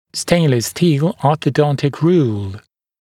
[‘steɪnləs stiːl ˌɔːθə’dɔntɪk ruːl][‘стэйнлэс сти:л ˌо:сэ’донтик ру:л]ортодонтическая линейка из нержавеющей стали